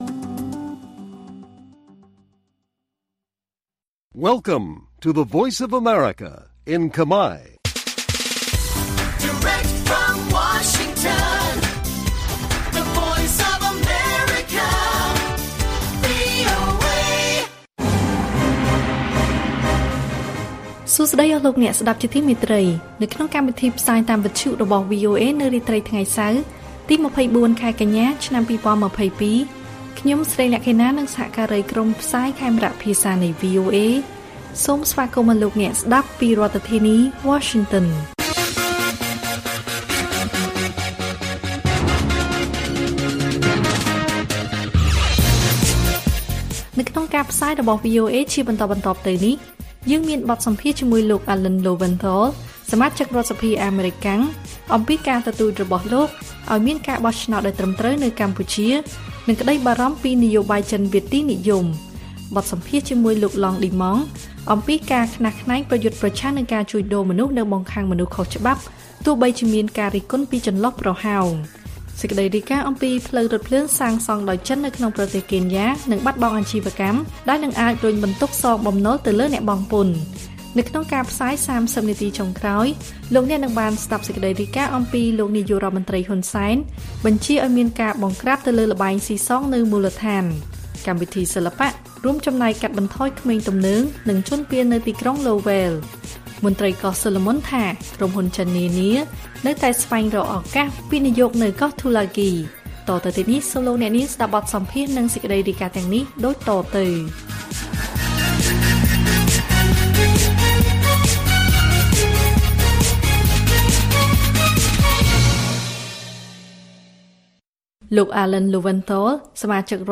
ព័ត៌មានថ្ងៃនេះមានដូចជា សមាជិកសភាអាមេរិកលោក Alan Lowenthal ទទូចឱ្យបោះឆ្នោតត្រឹមត្រូវនិងបារម្ភពីចិនវាតទីនៅកម្ពុជា។ បទសម្ភាសន៍អំពីការខ្នះខ្នែងប្រយុទ្ធប្រឆាំងការជួញដូរមនុស្សនិងបង្ខាំងមនុស្សខុសច្បាប់ ទោះបីជាមានការរិះគន់ពីចន្លោះប្រហោងនិងព័ត៌មានផ្សេងៗទៀត៕